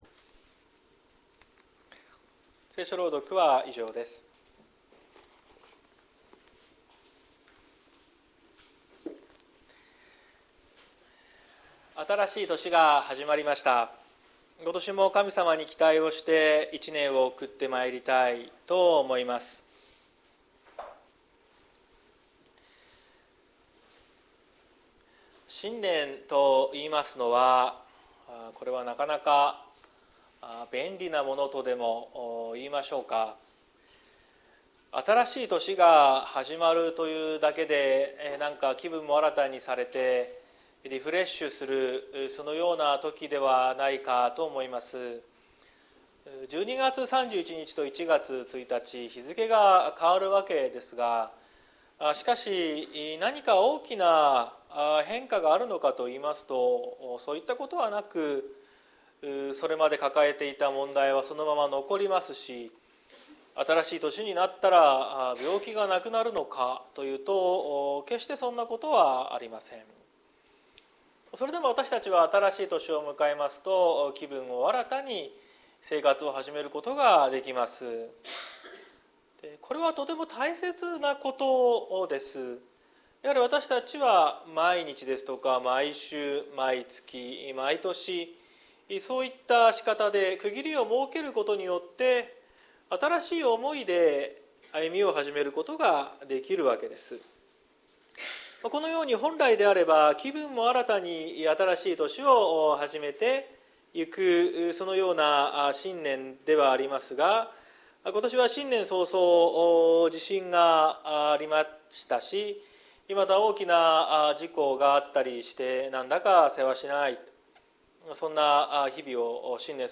2024年01月07日朝の礼拝「恵みのあふれる年」綱島教会
綱島教会。説教アーカイブ。